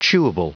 Prononciation du mot : chewable
chewable.wav